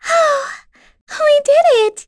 Yuria-Vox_Happy4.wav